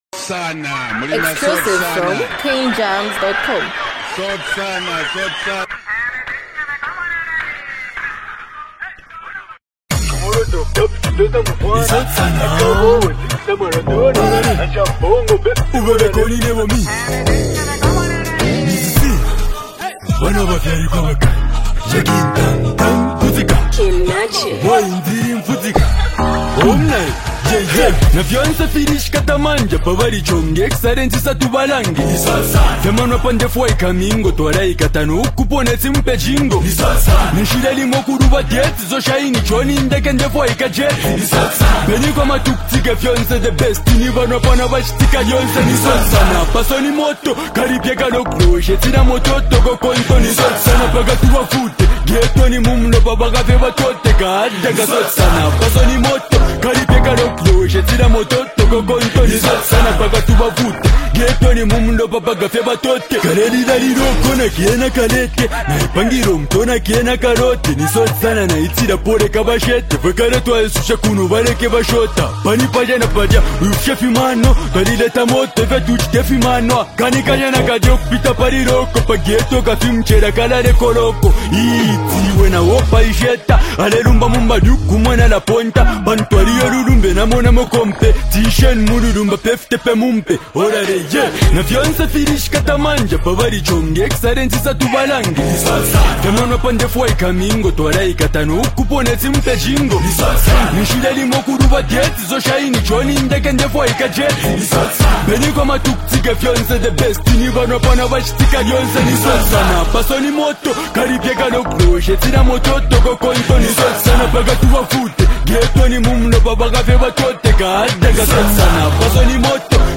It’s a lively and enjoyable track